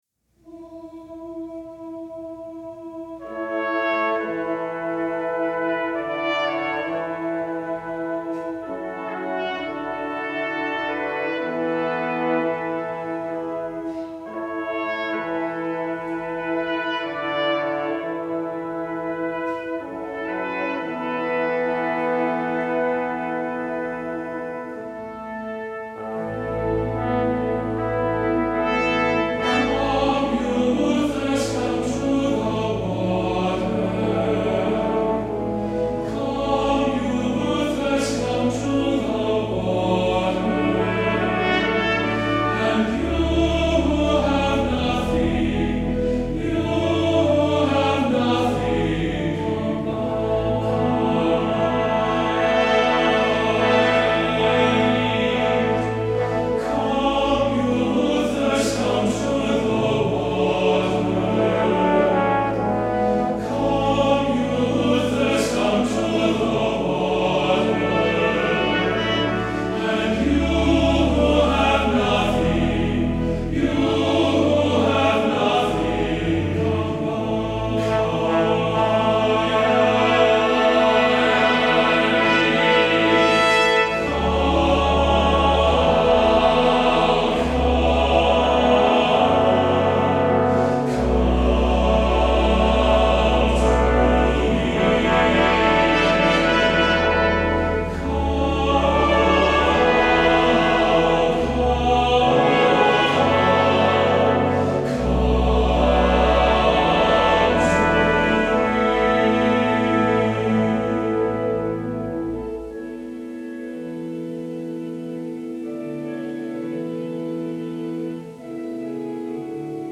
Voicing: TTBB Brass Sextet